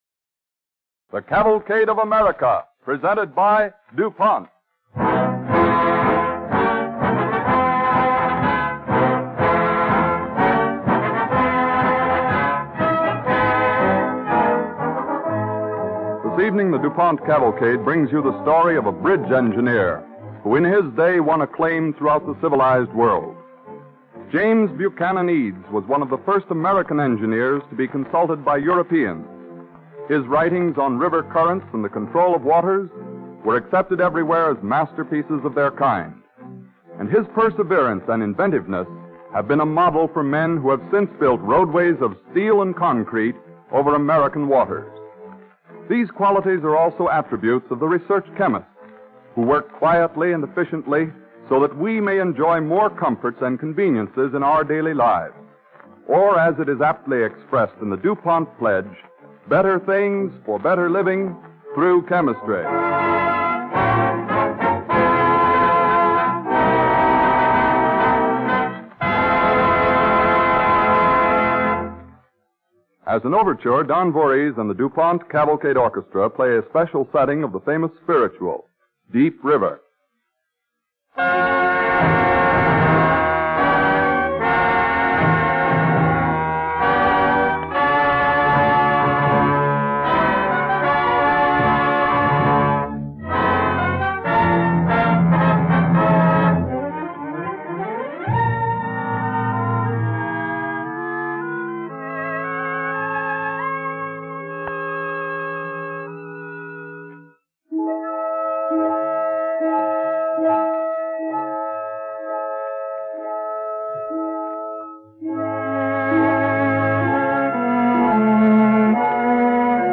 With announcer Dwight Weist